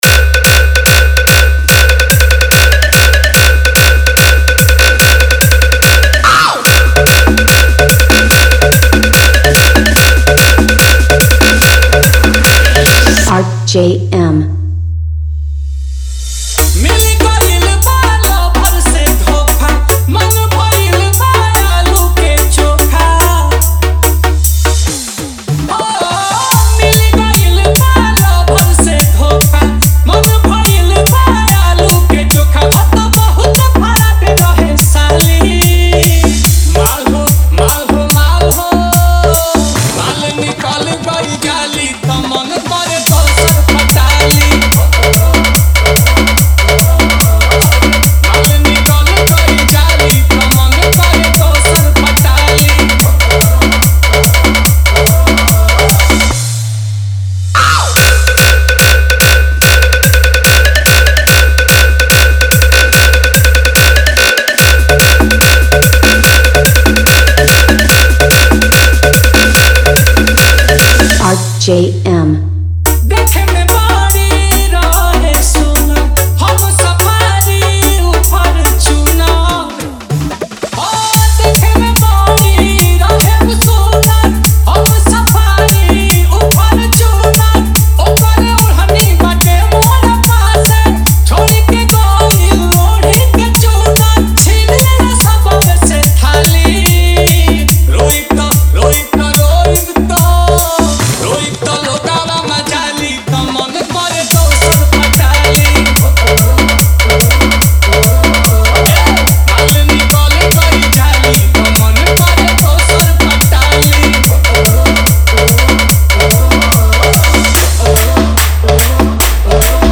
Upcoming: - 2025 Special Bhojpuri EDM Tahalka Song